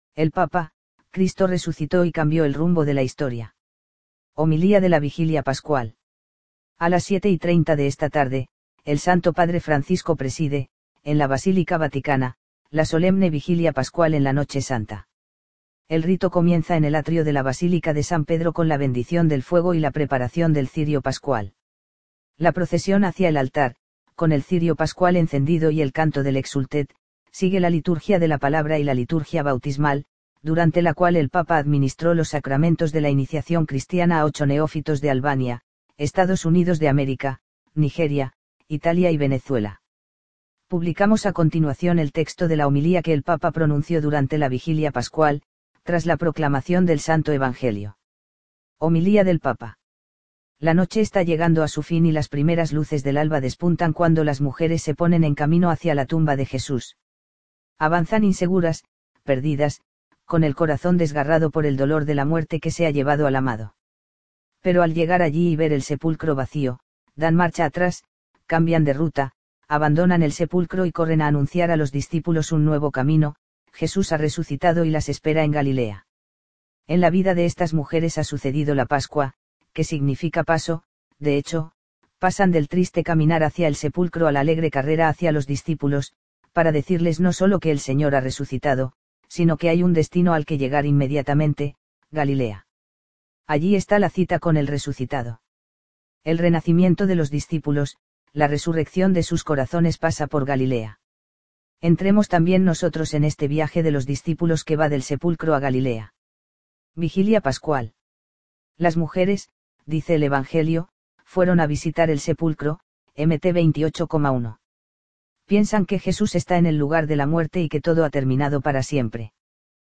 Homilía de la Vigilia Pascual
A las 19.30 horas de esta tarde, el Santo Padre Francisco preside, en la Basílica Vaticana, la solemne Vigilia Pascual en la Noche Santa.